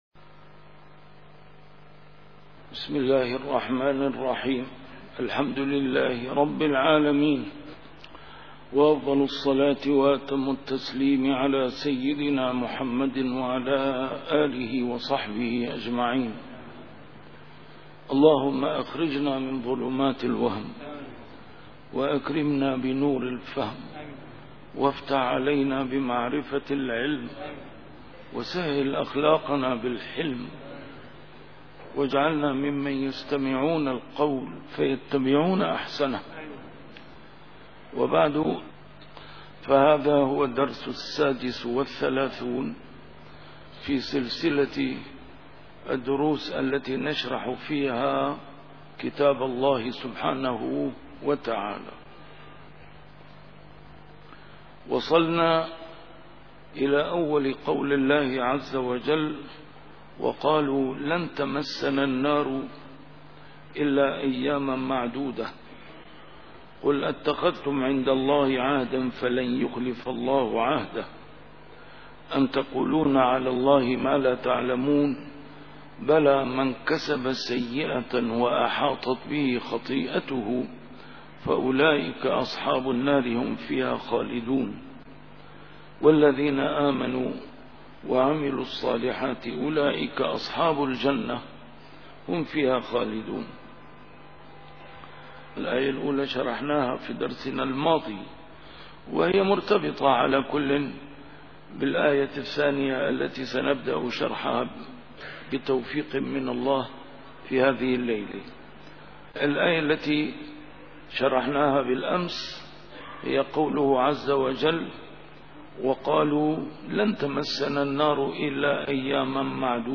A MARTYR SCHOLAR: IMAM MUHAMMAD SAEED RAMADAN AL-BOUTI - الدروس العلمية - تفسير القرآن الكريم - 36- سورة البقرة: الآية 80 - 82